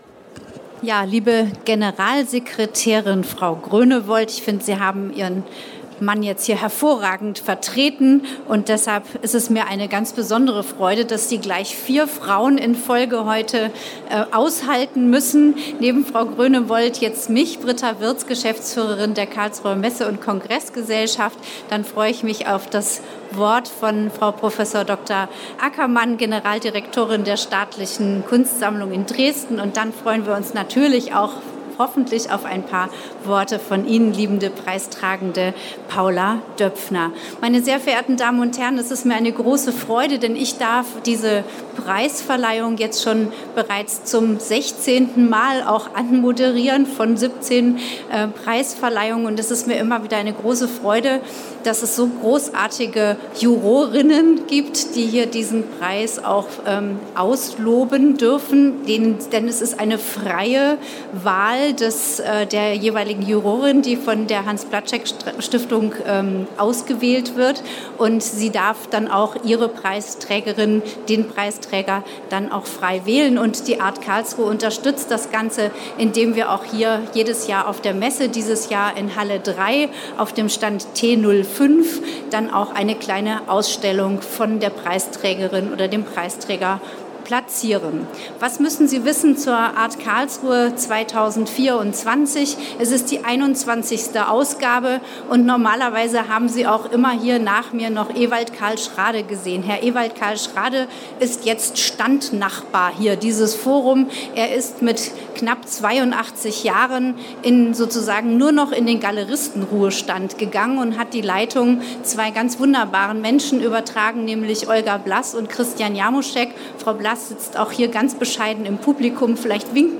Reden zur Preisverleihung